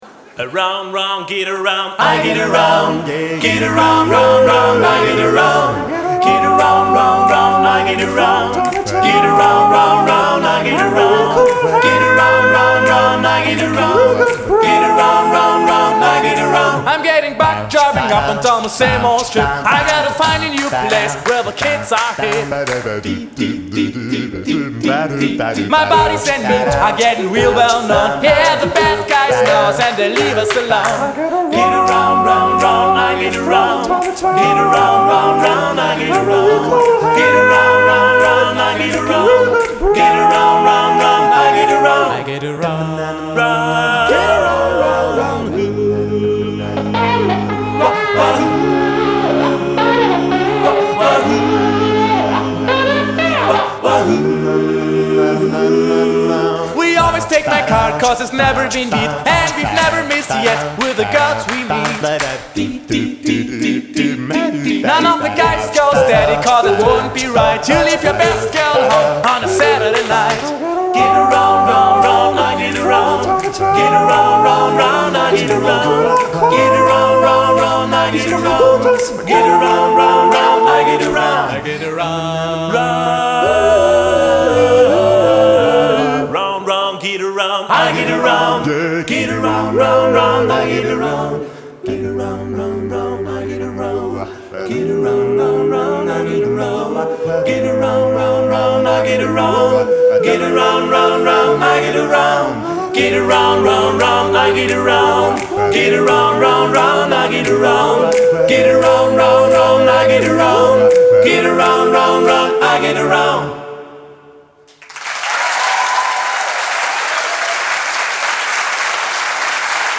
die Essenz aus den besten Konzerten der Jahre´96 und ´97